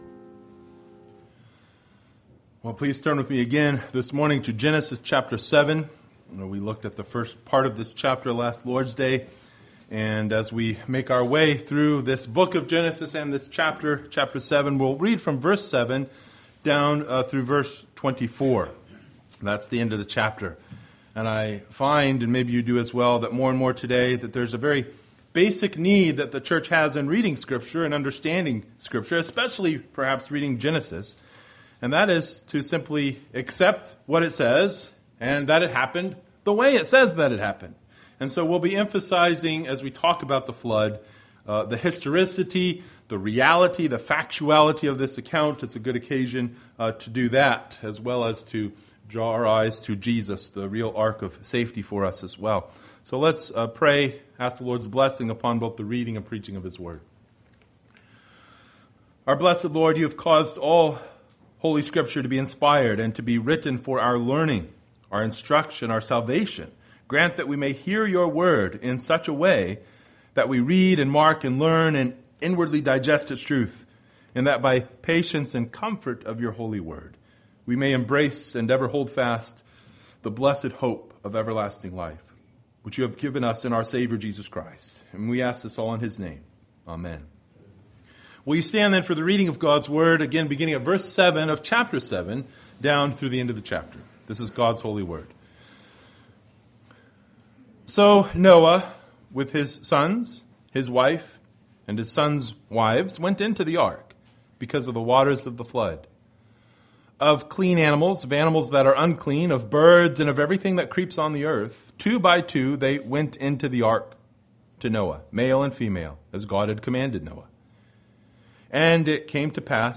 Genesis 7:7-24 Service Type: Sunday Morning Bible Text